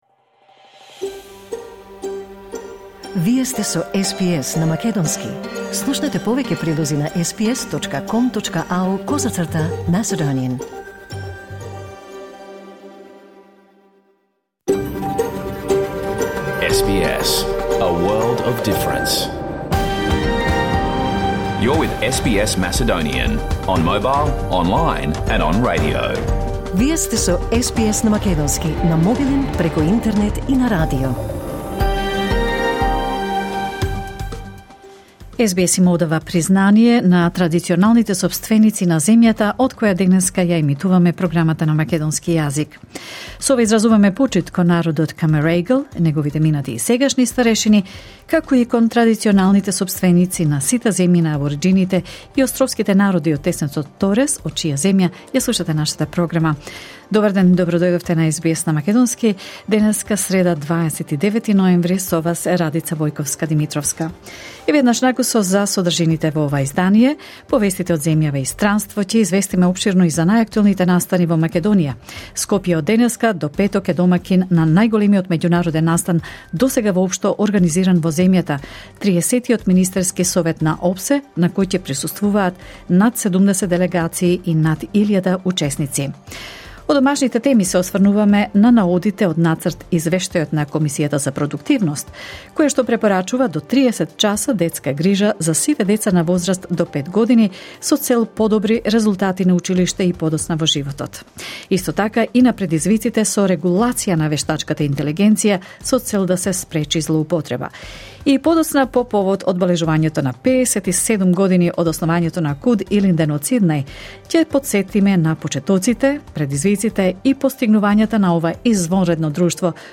SBS Macedonian Program Live On Air 29 November 2023